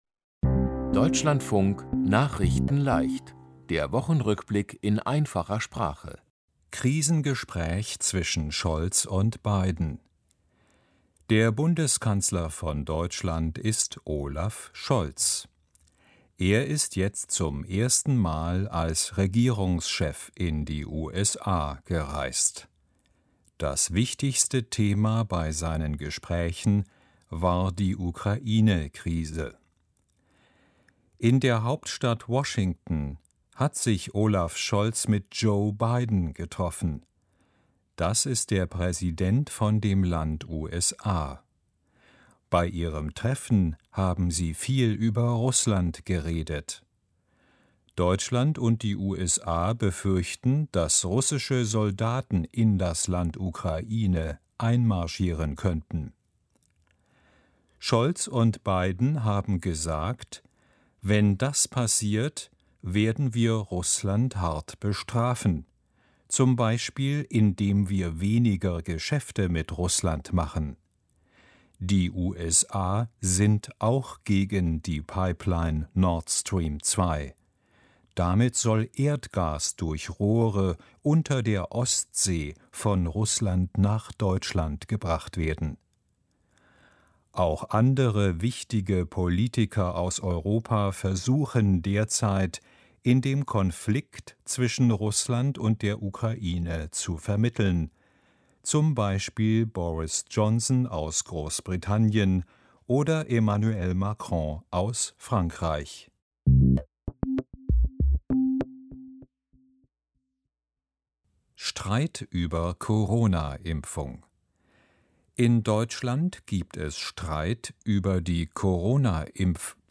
Der Wochen-Rückblick in Einfacher Sprache